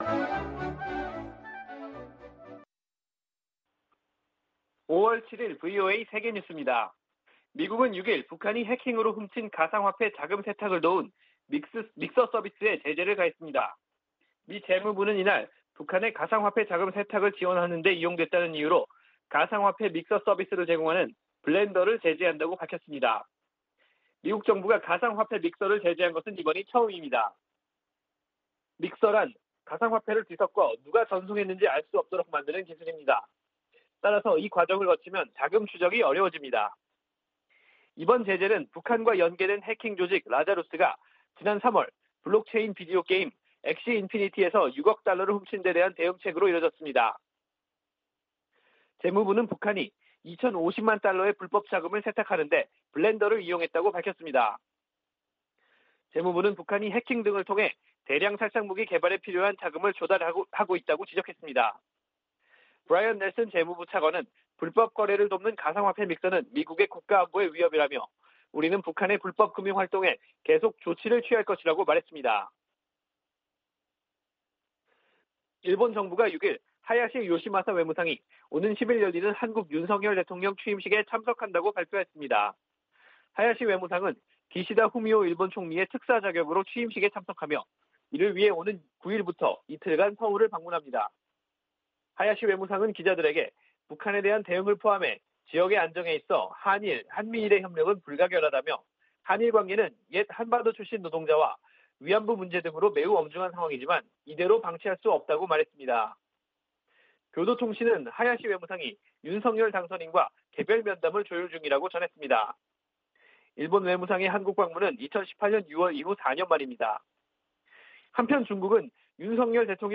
VOA 한국어 아침 뉴스 프로그램 '워싱턴 뉴스 광장' 2022년 5월 7일 방송입니다. 백악관은 조 바이든 대통령의 한일 순방에서 ‘확장억지’ 약속과, 북한 문제가 중점 논의 될 것이라고 밝혔습니다. 미 국무부는 북한의 거듭되는 미사일 발사에 대응이 따를 것이라는 분명한 신호를 보내야 한다고 강조했습니다. 미 상원이 필립 골드버그 주한 미국대사 인준안을 가결했습니다.